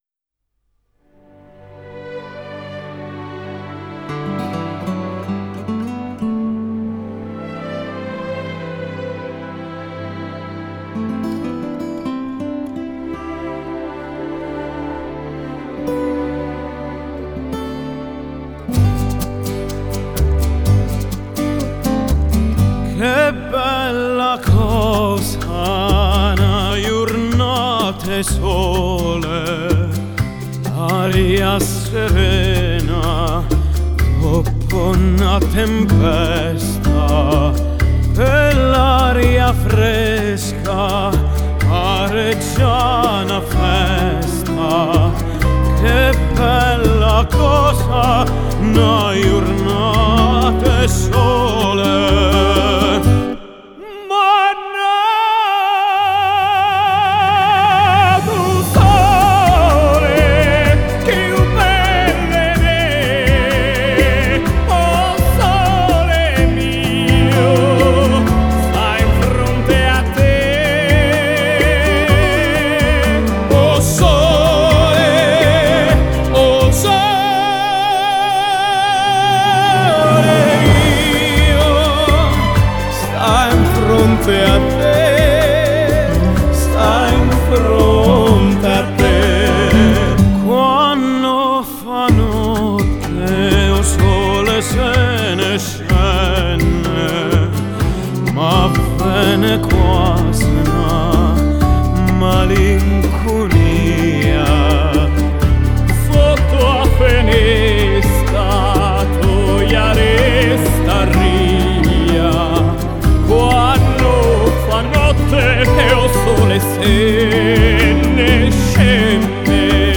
новое явление на поприще жанра классикал кроссовер.